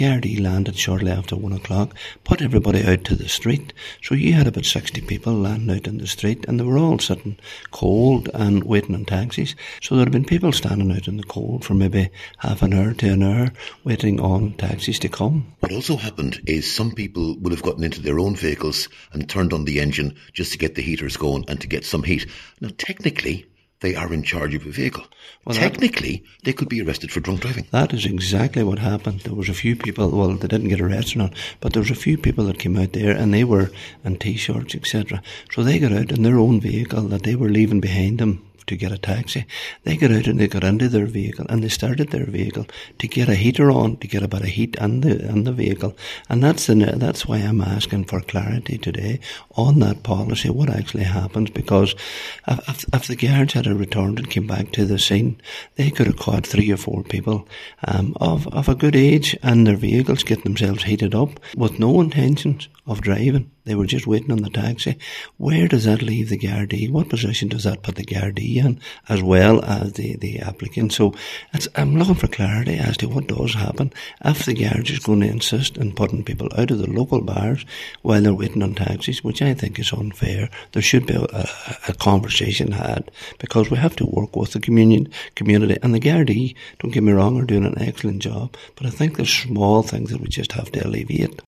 Cllr Paul Canning told the meeting that bars in the Newtowncunningham, Killea and Carrigans area were raided at the weekend.
Cllr Canning says this highlights a problem in rural areas that must be addressed…………